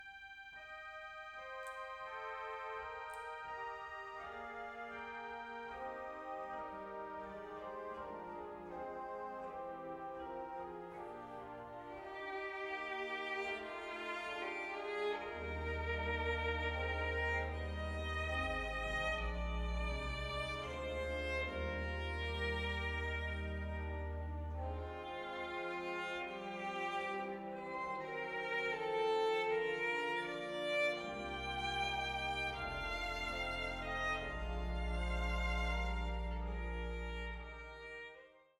Klassische Kirchenkonzerte